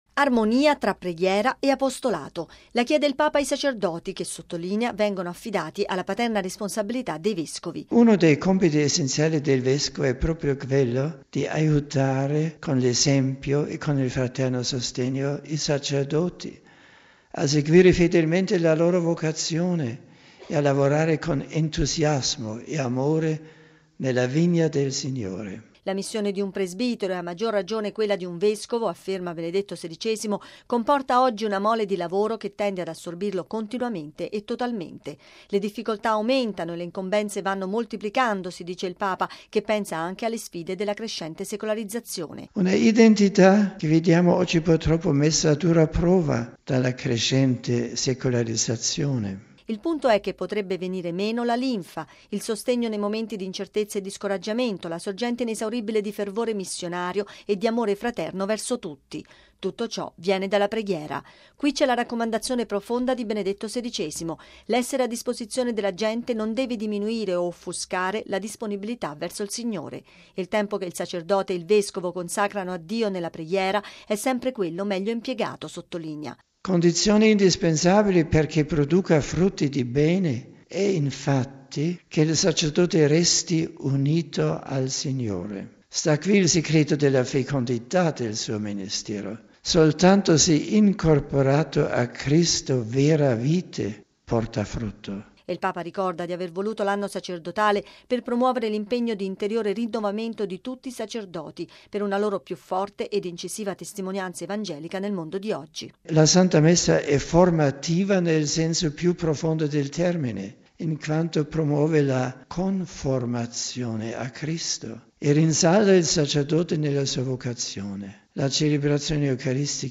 ◊   L’identità sacerdotale dei presbiteri è messa a dura prova dalla crescente secolarizzazione: lo ha affermato il Papa nell’incontro annuale promosso per i vescovi che da poco hanno intrapreso il loro ministero pastorale.